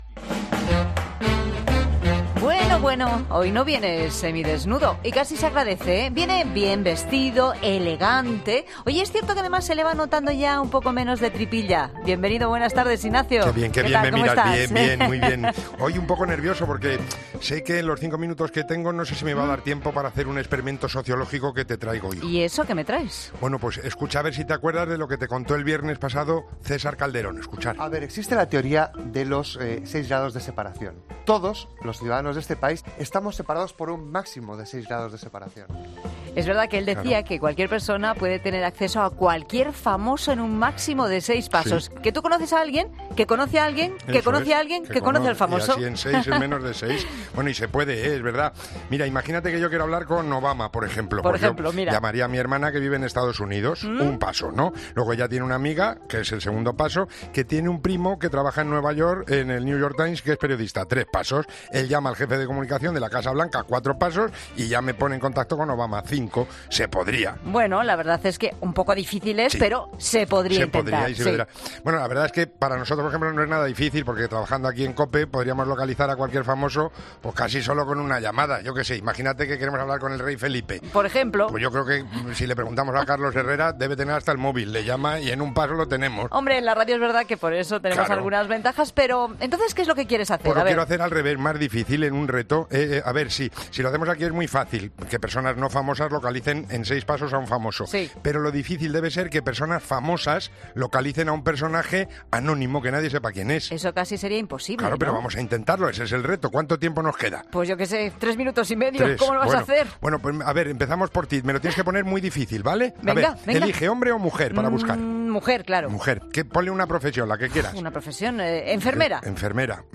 ¿Lo conseguirá en solo 5 minutos antes de que suene la bocina?